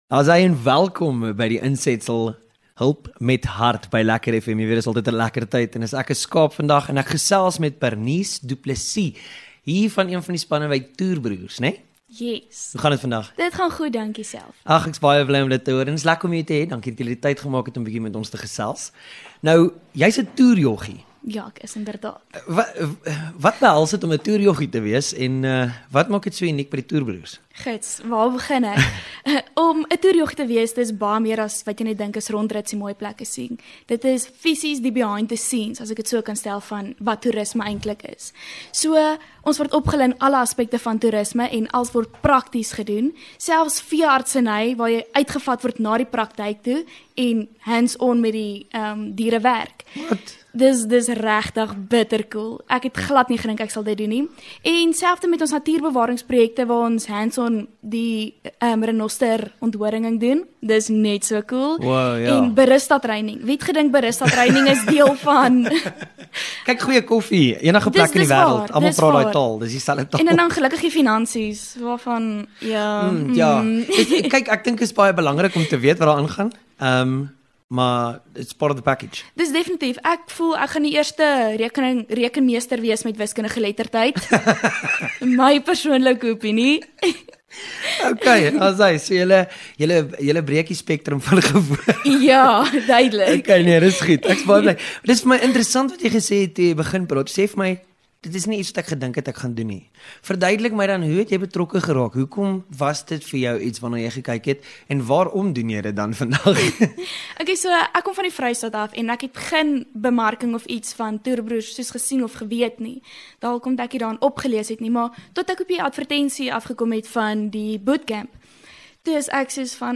LEKKER FM | Onderhoude